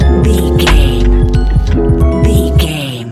Ionian/Major
C♭
laid back
Lounge
sparse
new age
chilled electronica
ambient
atmospheric
morphing